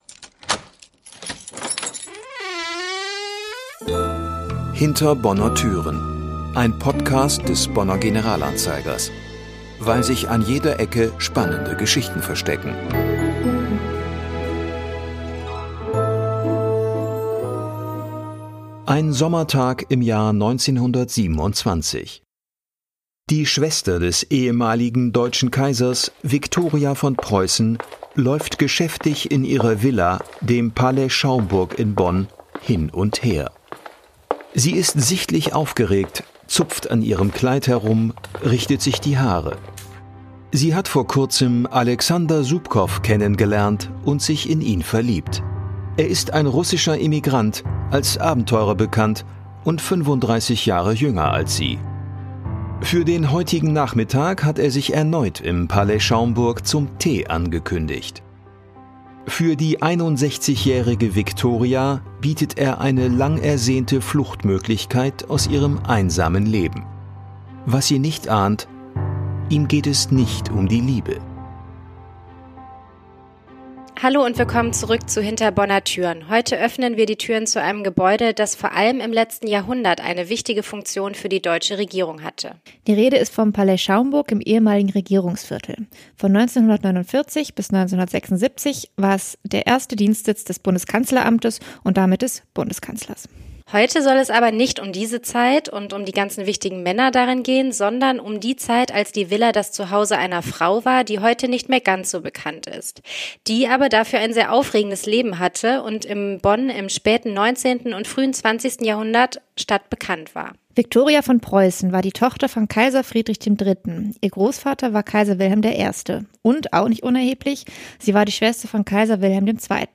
Beschreibung vor 1 Jahr In der neuen Podcast-Folge geht es um Prinzessin Viktoria von Preußen, die in Bonn für viel Aufsehen sorgte. Erst war sie für ihre Feste im Palais Schaumburg bekannt, dann für eine verhängnisvolle Affäre. Entschuldigt bitte das "Ploppen" in dieser Folge - wir hatten ein kleines, mittlerweile behobenes Mikro-Problem.